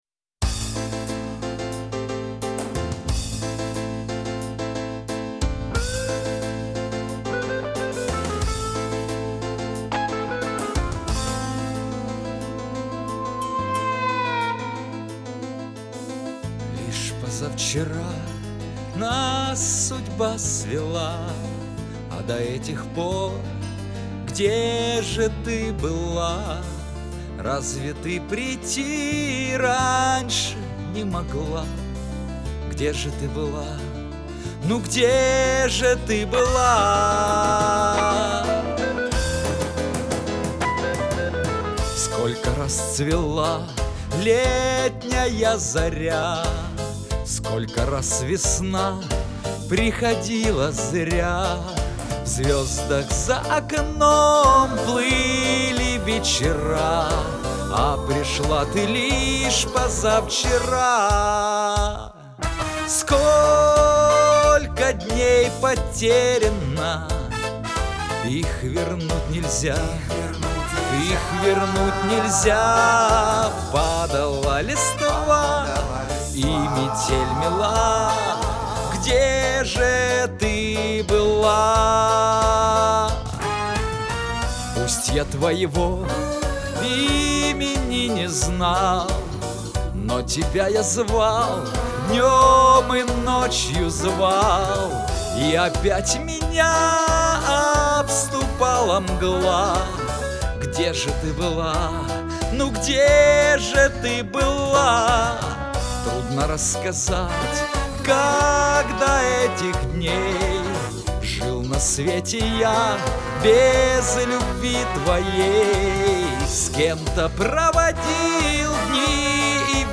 Такая светлая "тоска", поднимающая настроение
Да и качество записи - лучше.